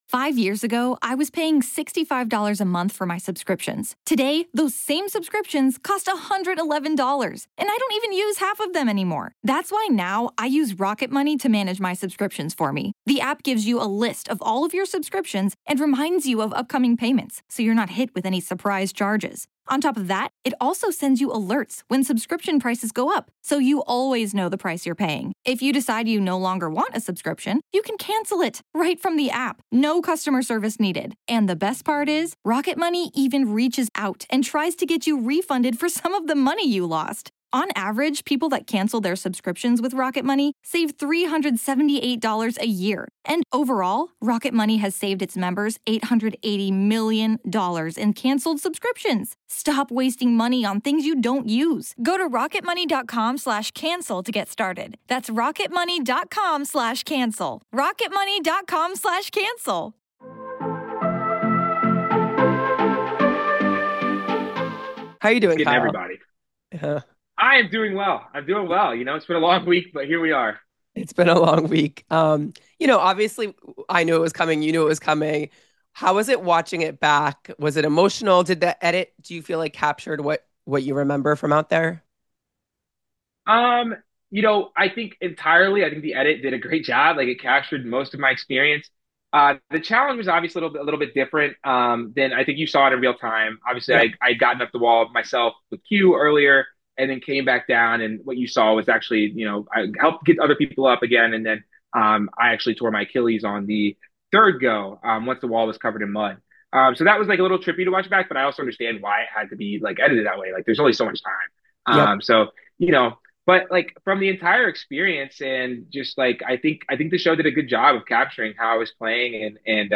Survivor 50 Exit Interview: Second Player Out